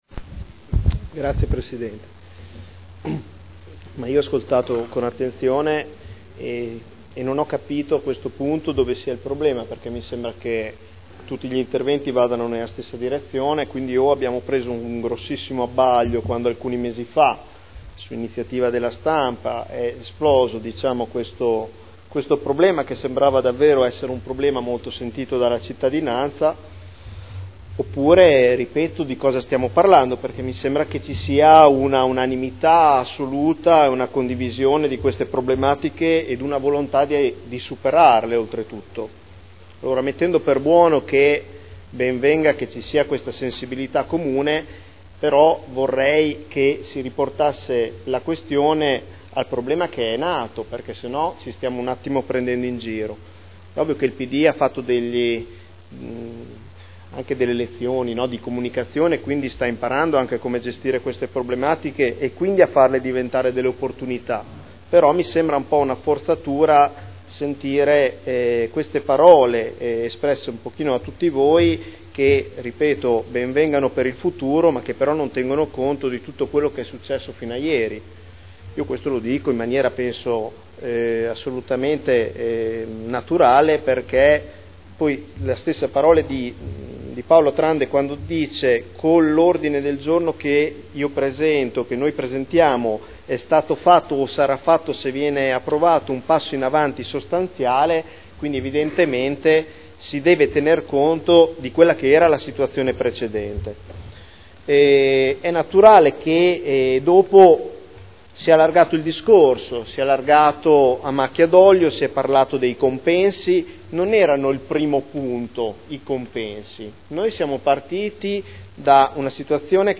Nicola Rossi — Sito Audio Consiglio Comunale
Seduta del 11/04/2011. Dibatto sui cinque Ordini del Giorno presentati aventi per oggetto le "Nomine"